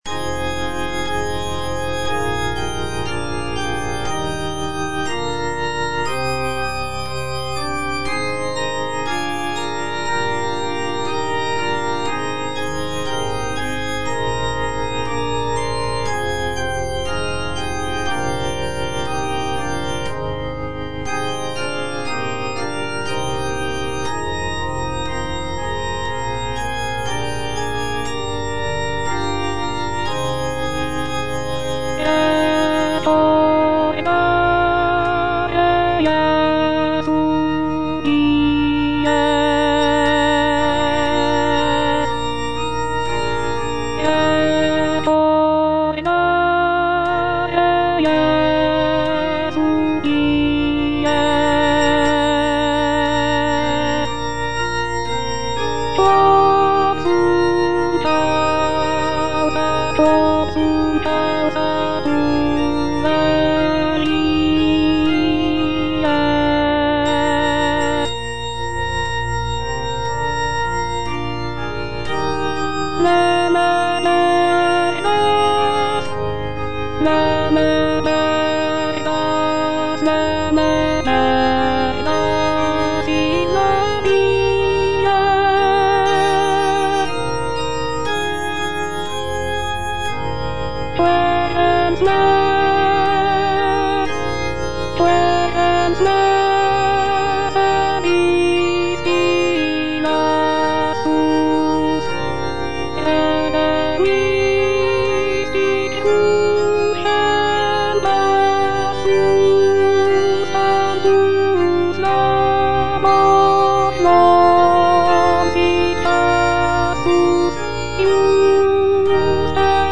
Alto (Voice with metronome) Ads stop
is a sacred choral work rooted in his Christian faith.